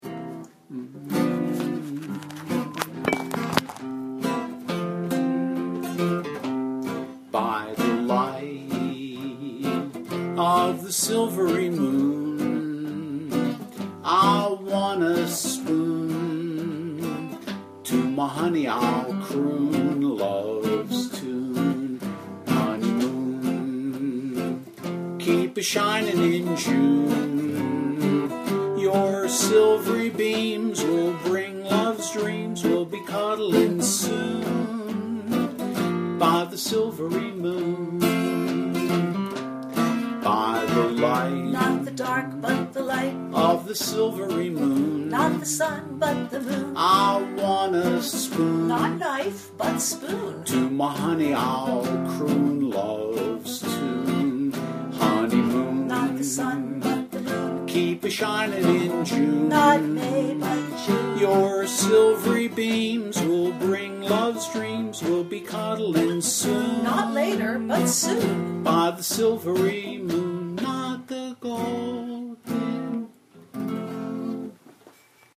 Chorus Only (F) with EZ Chords
Audio File – Sing and Play Along